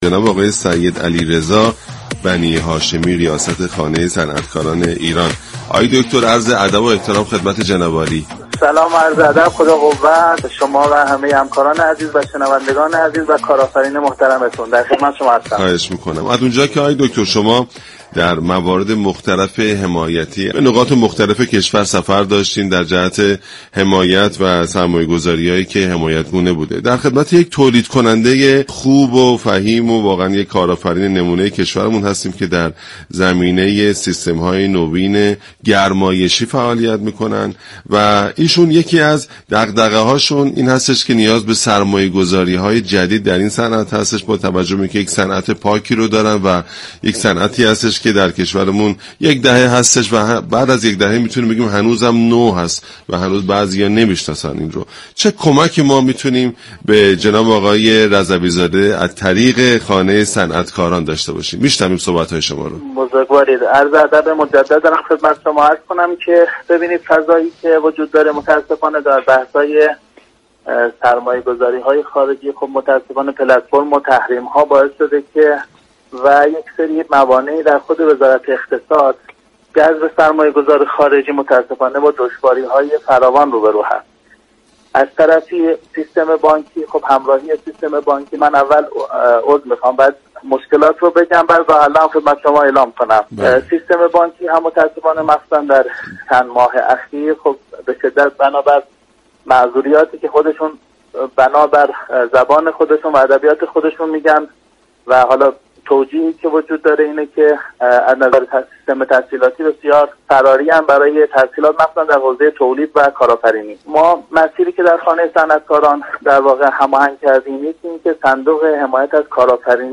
در گفت و گو با برنامه "بر بلندای همت" رادیو تهران